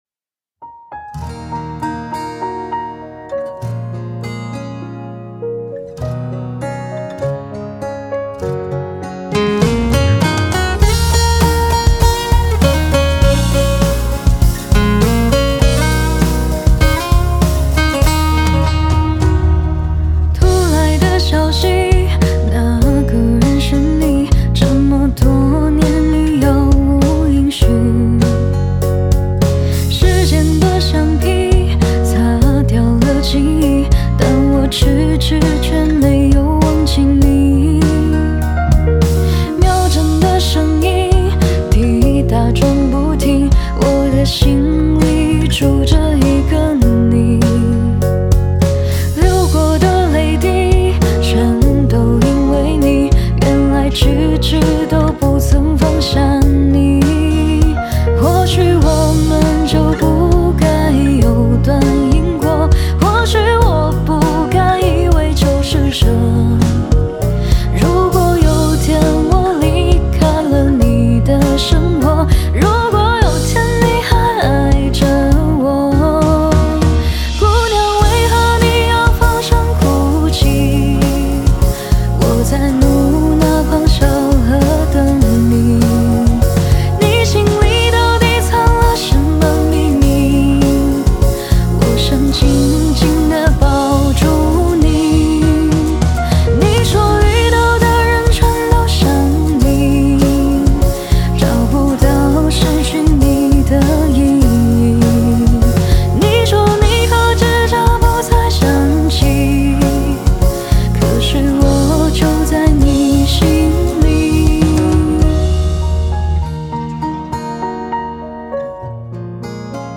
和声
吉他